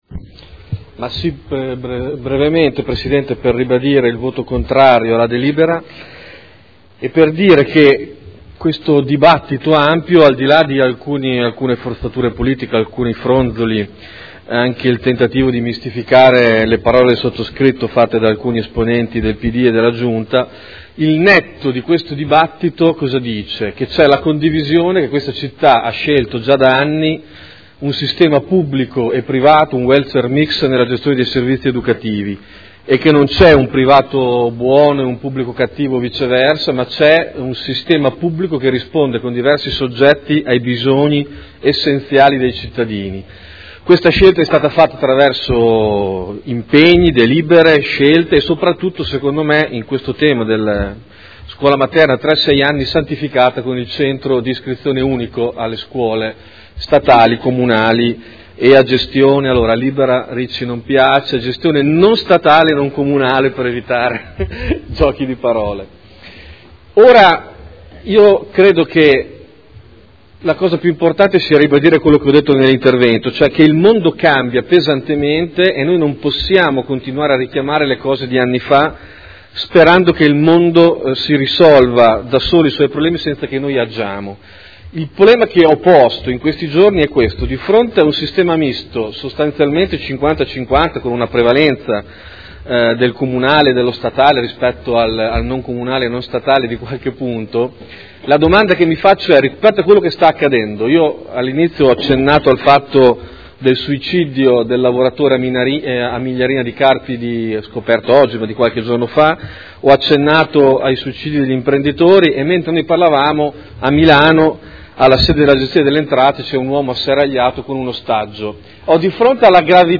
Davide Torrini — Sito Audio Consiglio Comunale